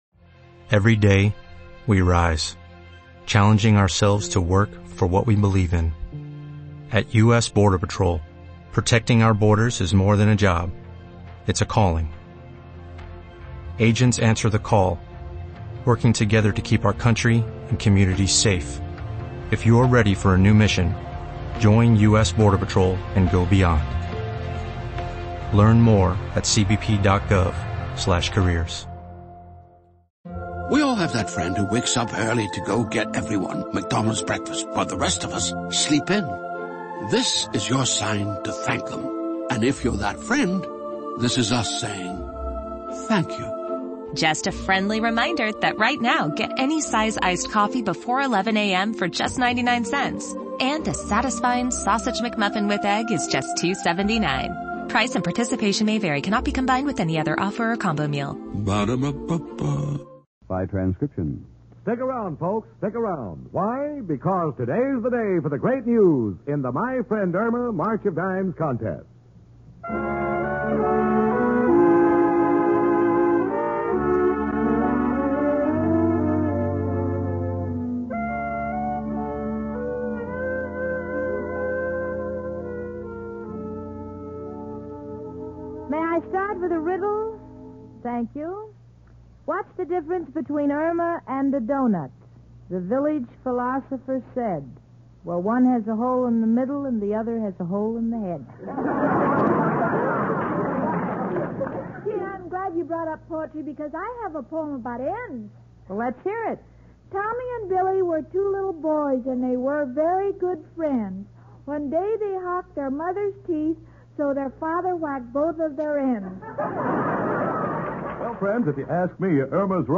"My Friend Irma," the classic radio sitcom that had audiences cackling from 1946 to 1952! It was a delightful gem of a show, chronicling the misadventures of Irma Peterson, a ditzy yet endearing blonde, and her level-headed roommate Jane Stacy. Irma, played to perfection by the inimitable Marie Wilson, was the quintessential "dumb blonde."